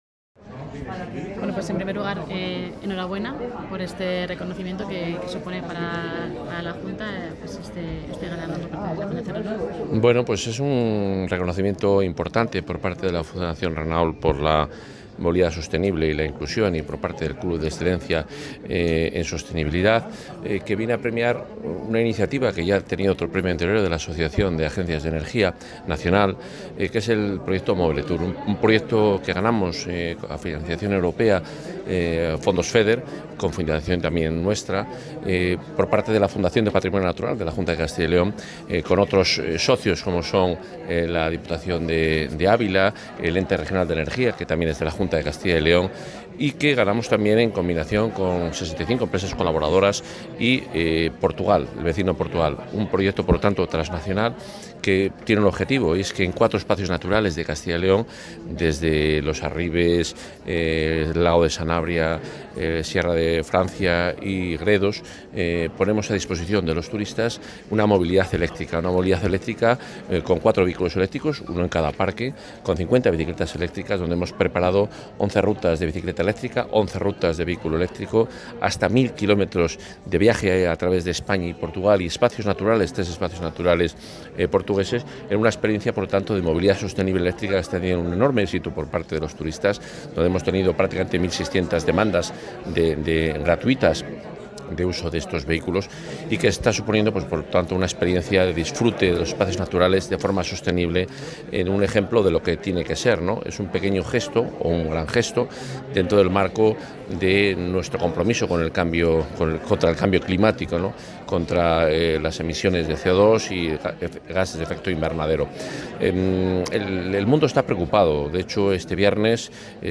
Audio consejero.